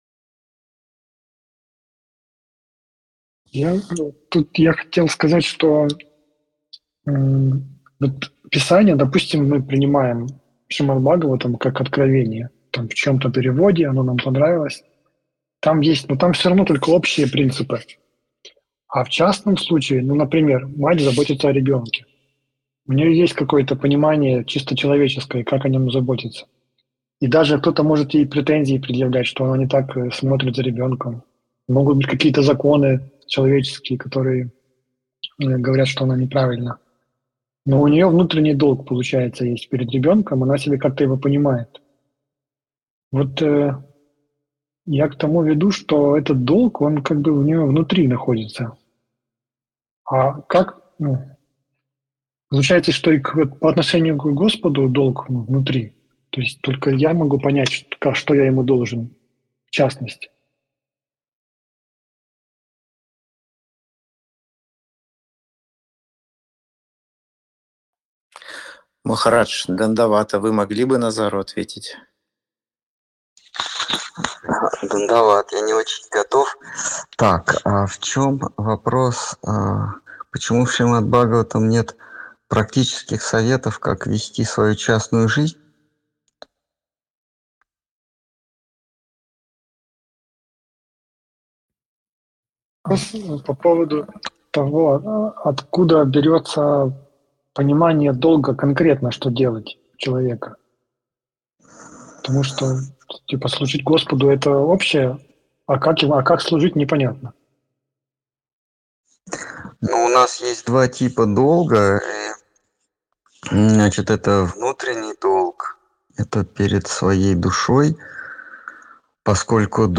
Ответы на вопросы из трансляции в телеграм канале «Колесница Джаганнатха». Тема трансляции: Бхагавад Гита.